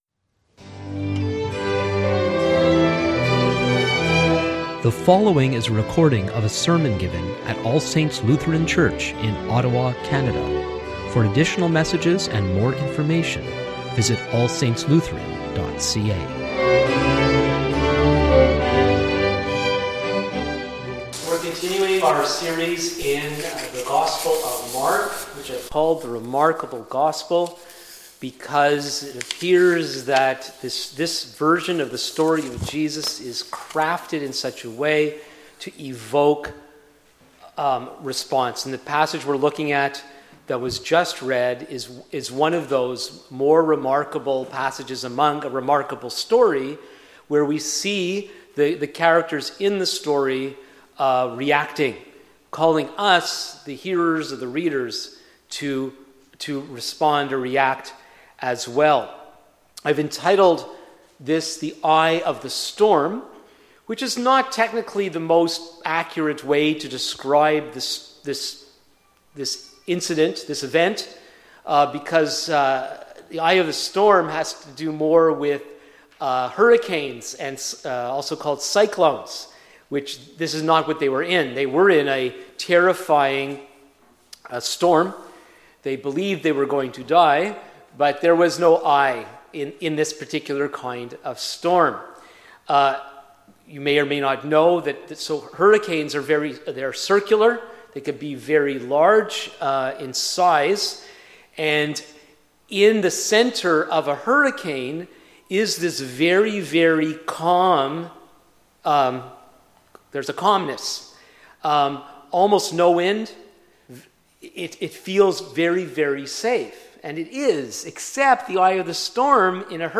Sermons | All Saints Lutheran Church